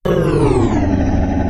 ghoul_dies.ogg